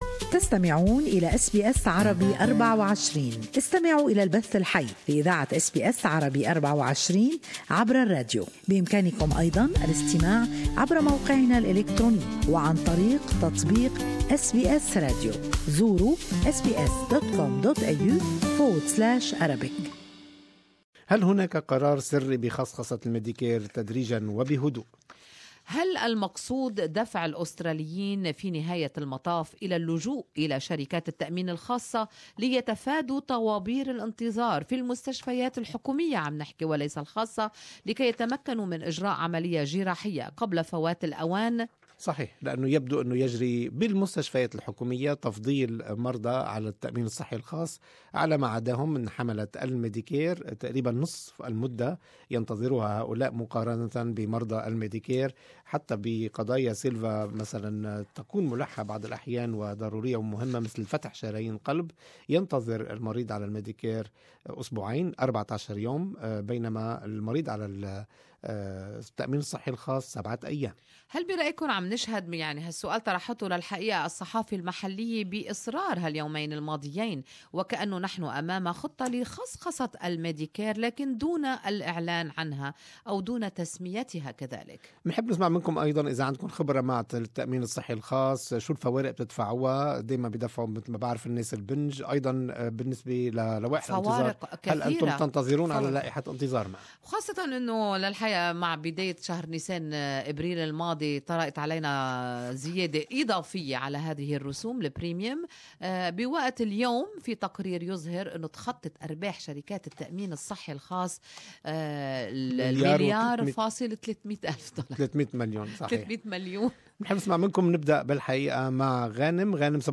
A new report suggests that public hospitals are favoring patients with private health care when it comes to waiting lists. Good Morning Australia listeners share their opinions on this topic.